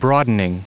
Prononciation du mot broadening en anglais (fichier audio)
Prononciation du mot : broadening